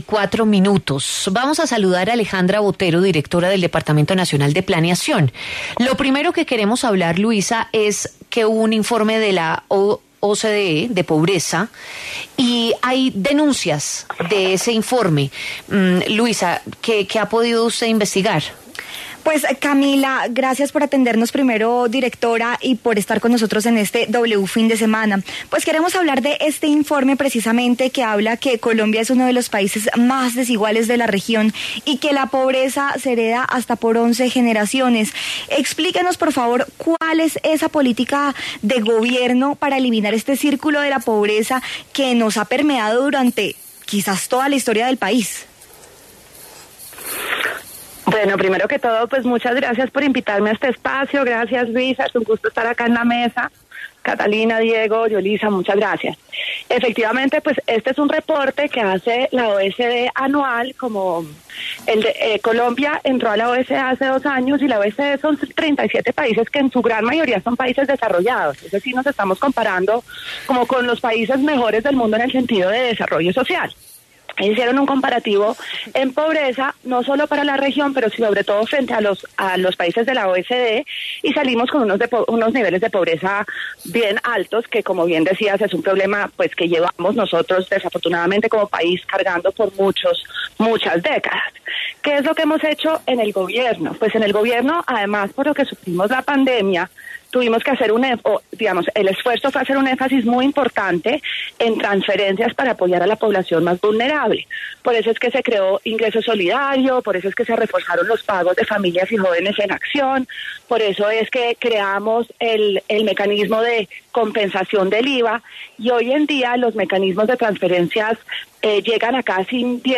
Alejandra Botero, directora del Departamento Nacional de Planeación, habla en W Fin de Semana sobre la advertencia que Colombia es uno de los países más desiguales de la región.